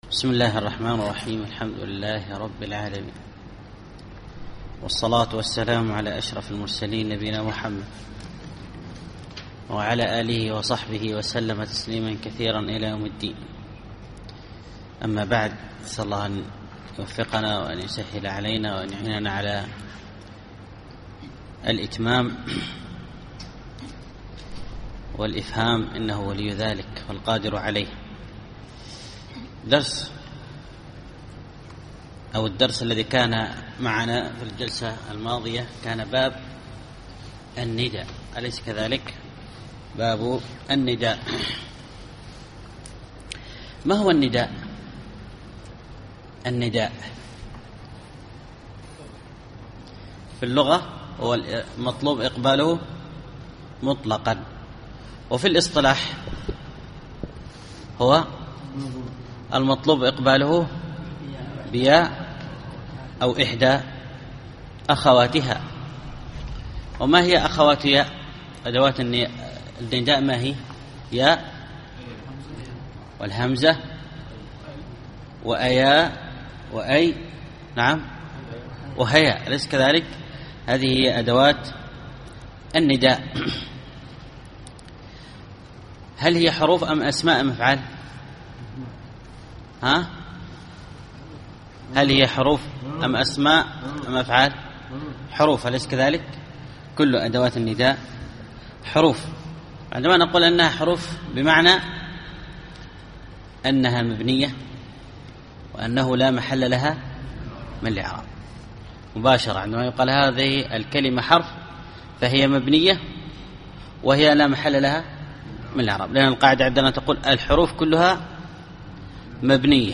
الدرس الثالث والعشرون الأبيات 233-242